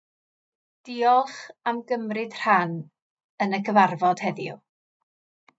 Dee-olch am gumreed rh-an uhn yhh cyvarvod